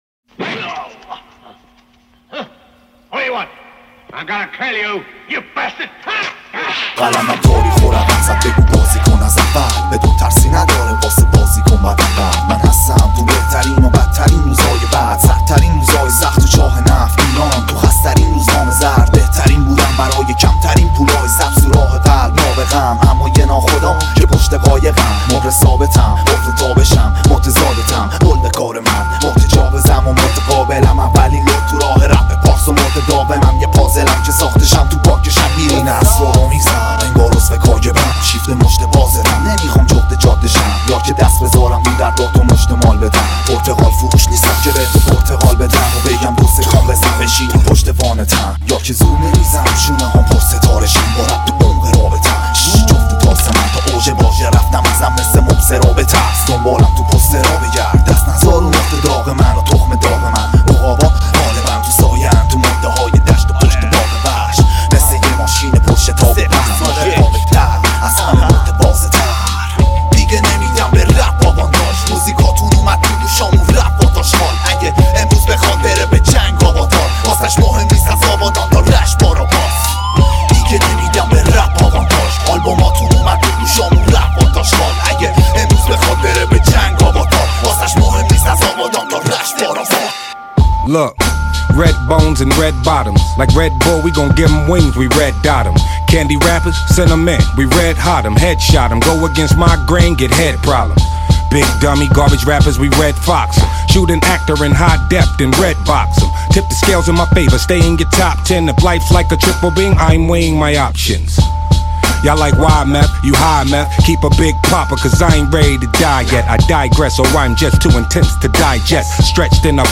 ریمیکس گنگ و خفن